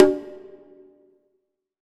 CONGA 26.wav